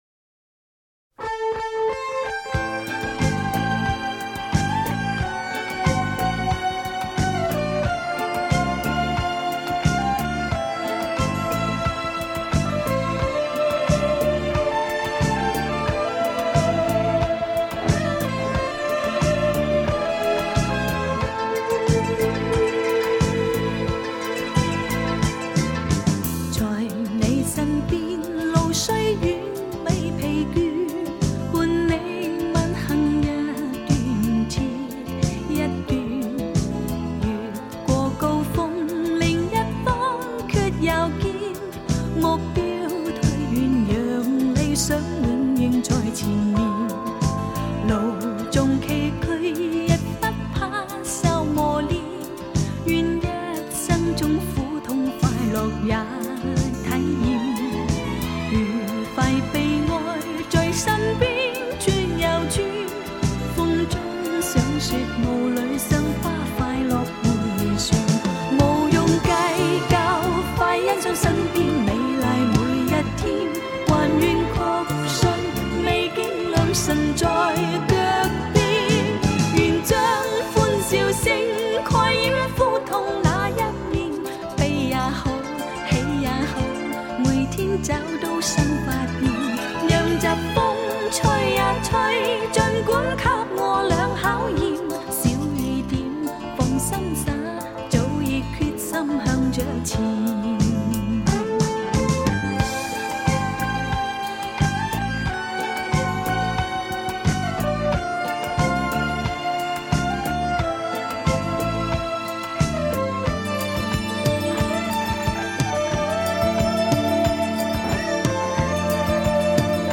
音质淳朴
是比较接近母带的版本